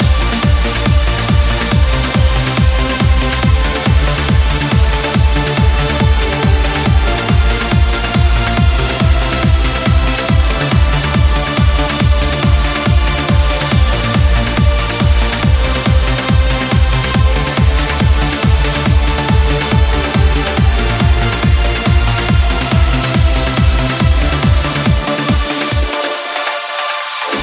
uplifting trance
Genere: trance, uplifting trance